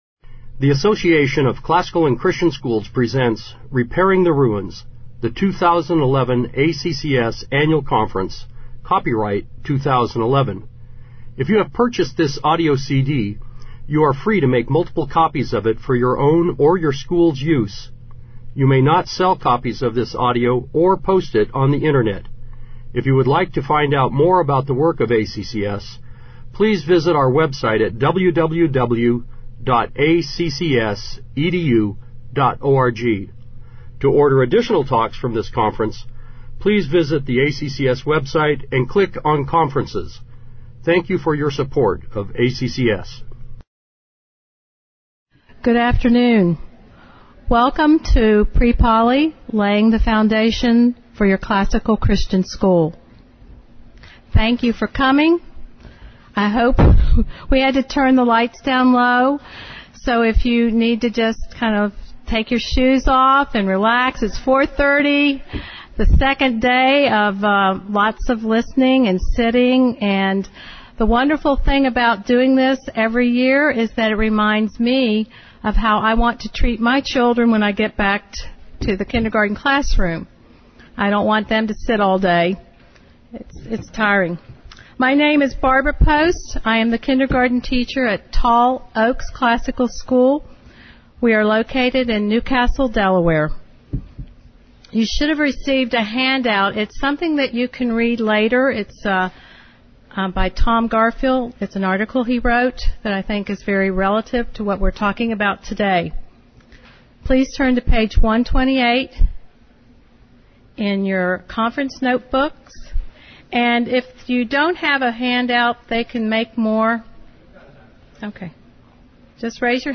2011 Workshop Talk | 0:58:46 | K-6, General Classroom